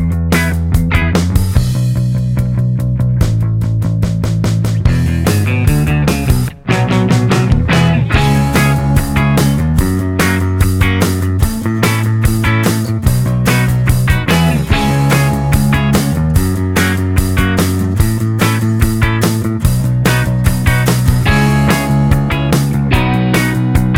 No Backing Vocals Rock 'n' Roll 3:17 Buy £1.50